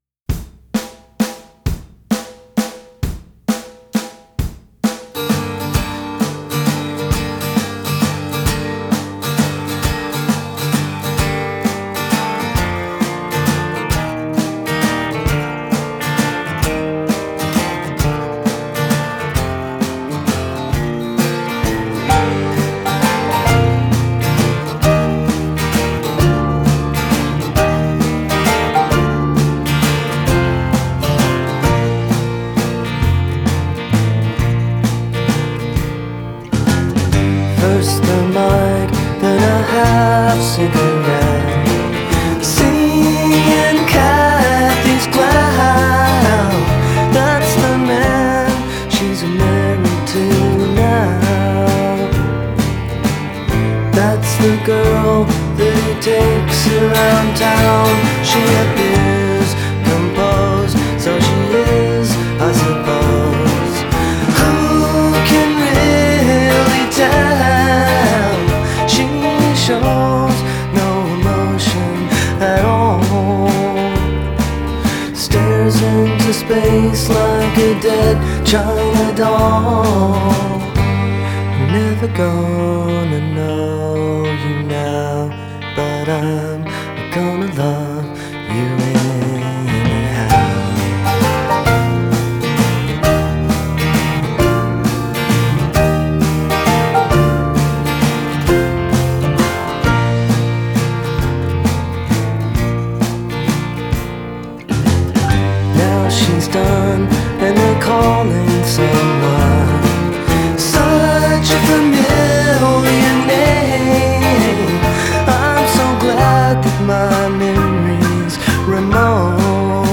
Genre: Indie Rock / Singer-Songwriter / Acoustic